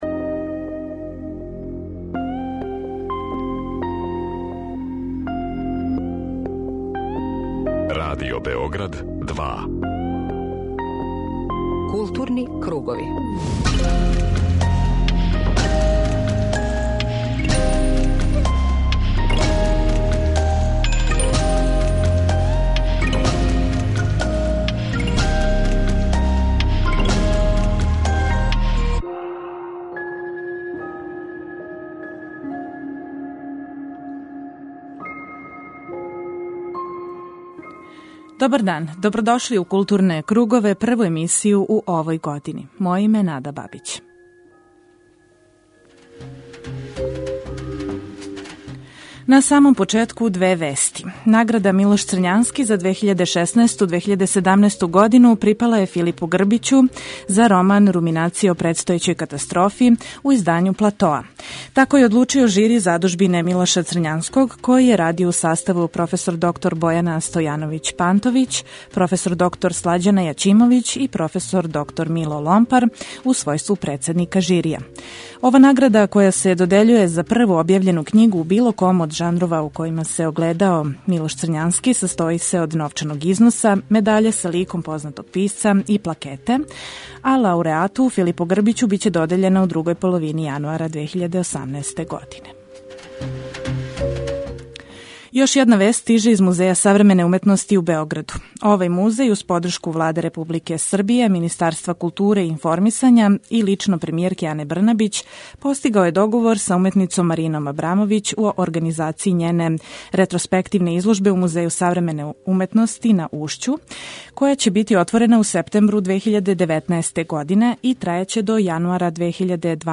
У данашњој емисији чућете разговор са Ласлом Блашковићем, управником Народне библиотеке Србије.